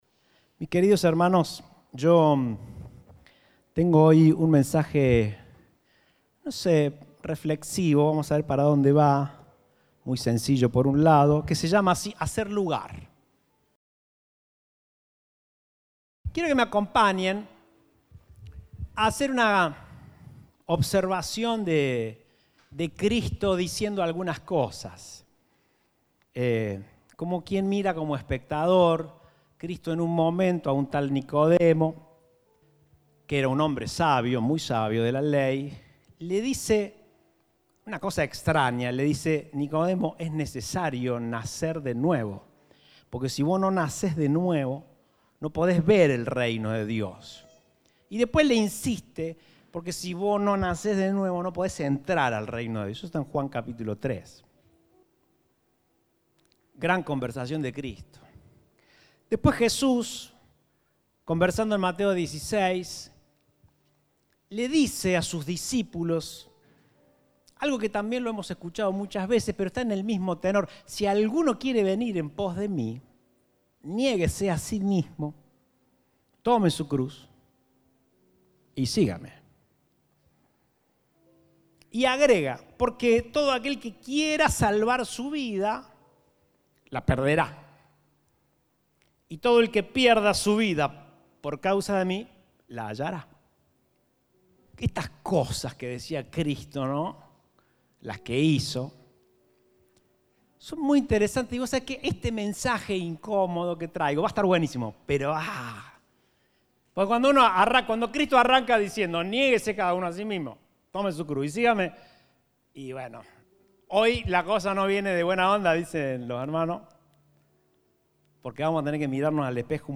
Compartimos el mensaje del Domingo 18 de Julio de 2021